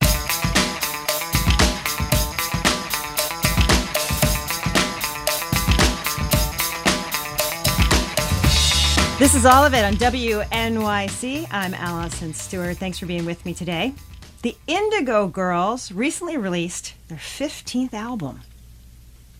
(captured from the webstream)
01. introduction (0:19)